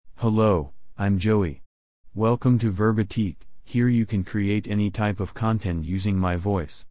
JoeyMale US English AI voice
Voice sample
Listen to Joey's male US English voice.
Male
Joey delivers clear pronunciation with authentic US English intonation, making your content sound professionally produced.